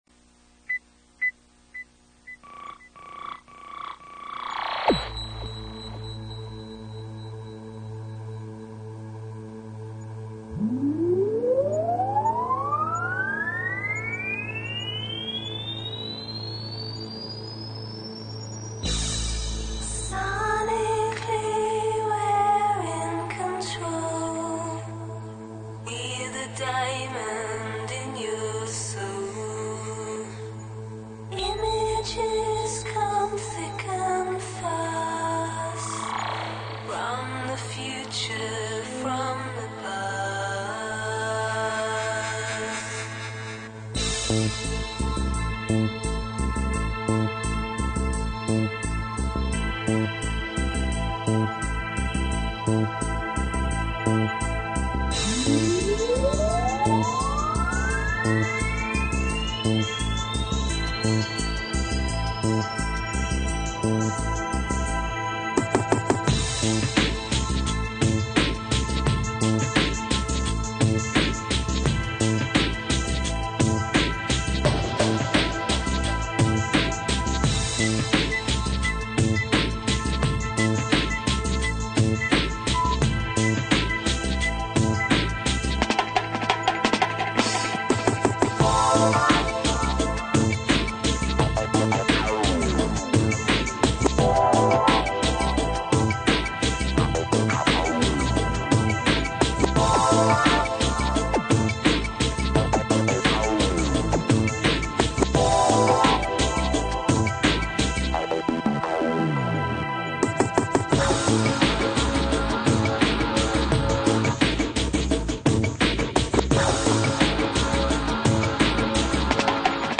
So, the second clip is cleaner.